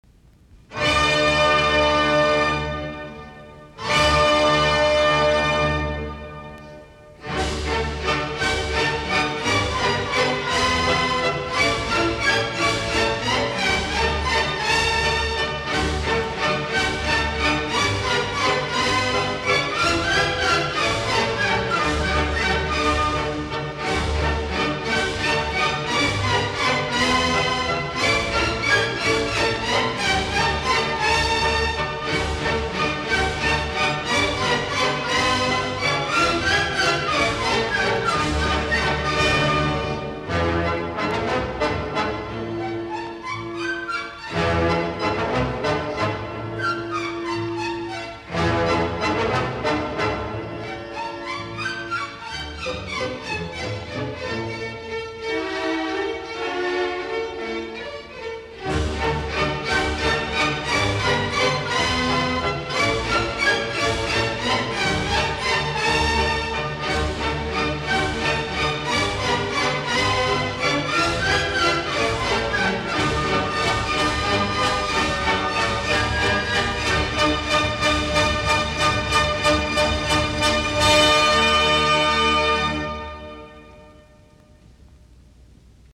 Soitinnus: Ork.